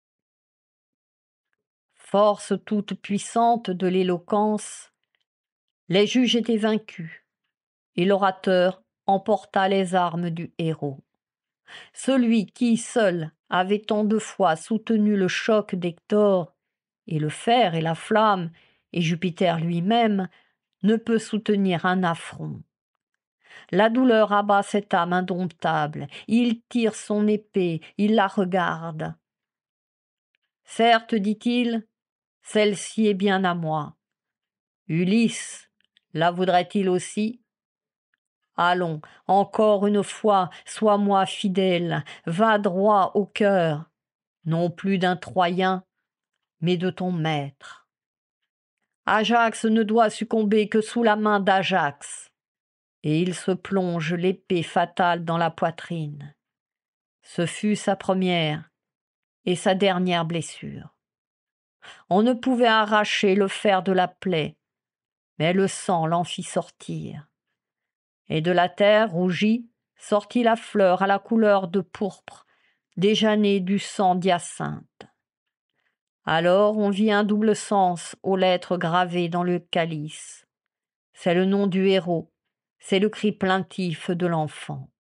Lecture de la métamorphose d'Ajax · GPC Groupe 1